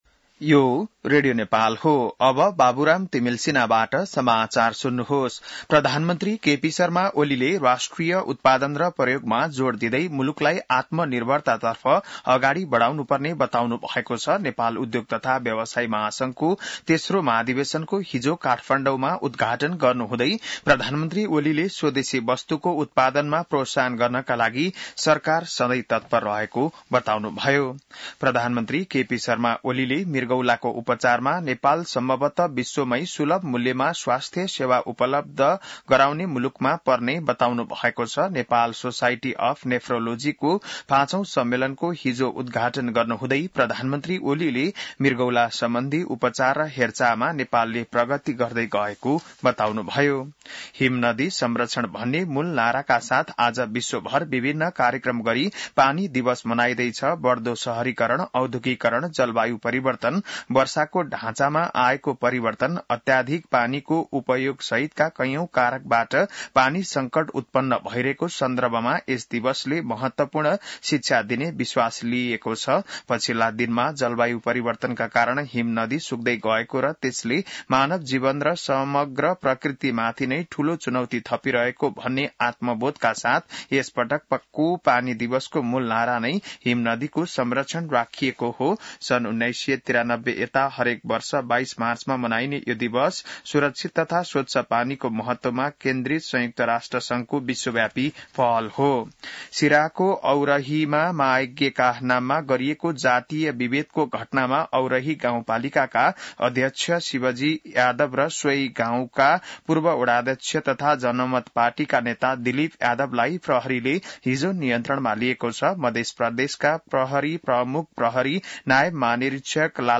बिहान १० बजेको नेपाली समाचार : ९ चैत , २०८१